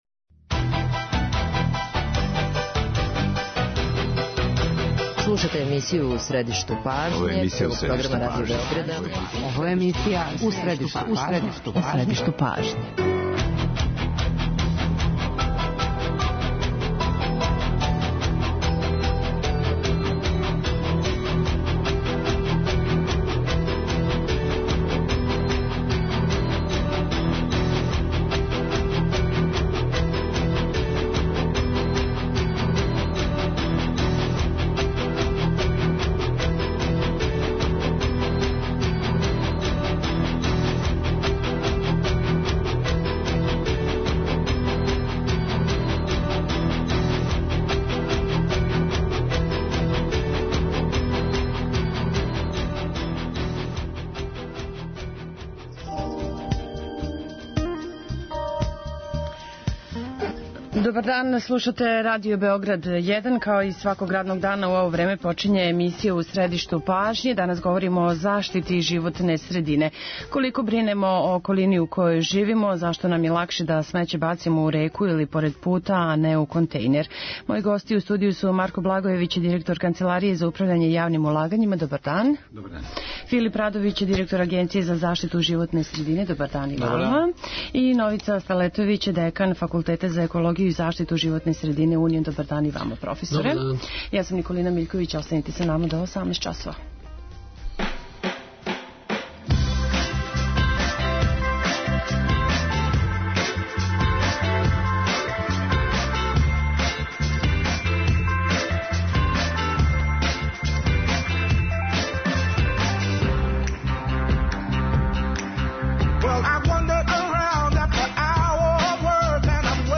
О томе говоре Марко Благојевић, директор Канцеларије за управљање јавним улагањима, Филип Радовић, директор Агенције за заштиту животне средине